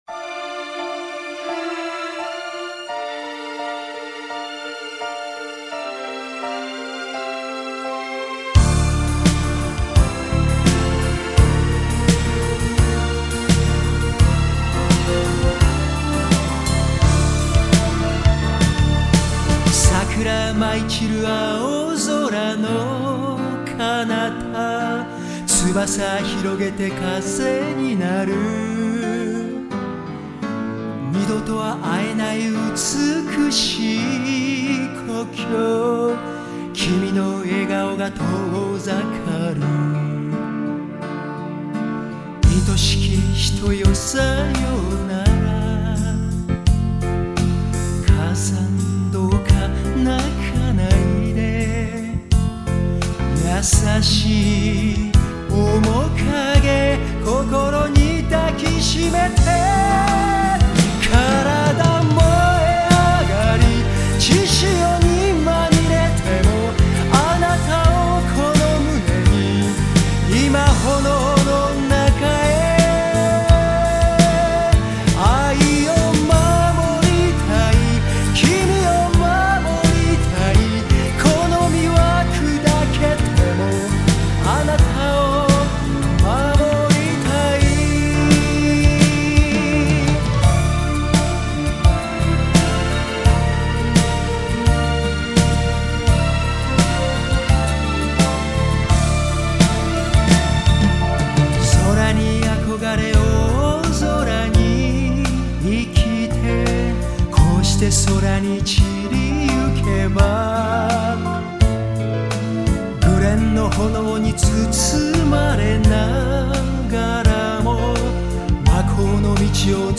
ヴォーカル・バージョン